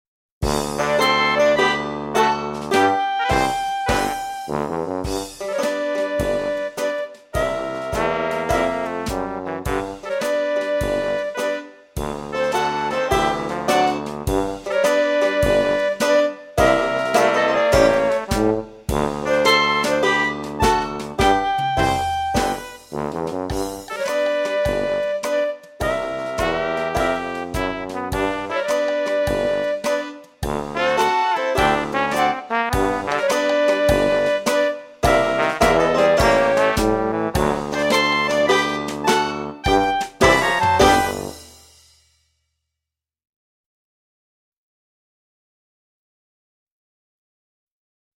VS First Finger Rag (backing track)